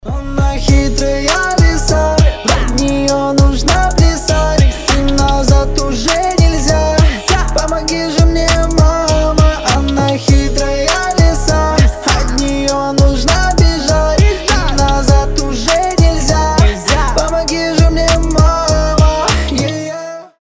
мужской голос
Хип-хоп